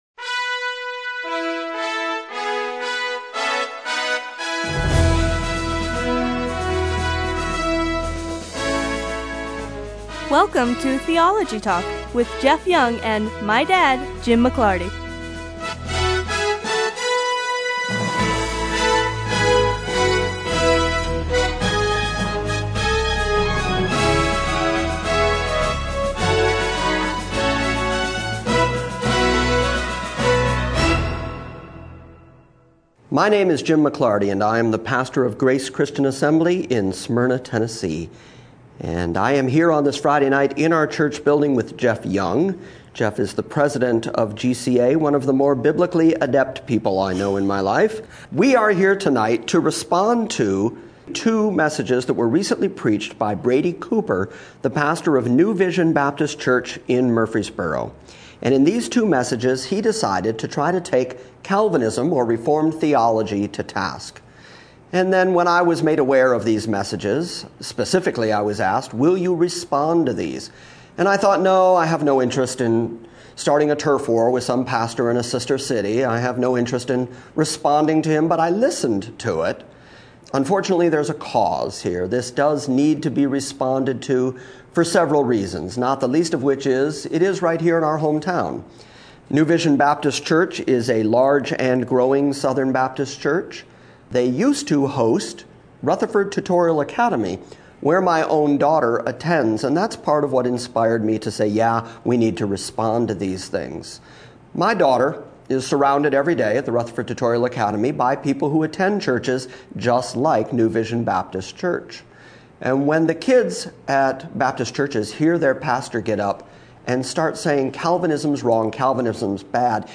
Long before podcasts were a thing, I sat down with two different fellows at GCA and discussed theological things.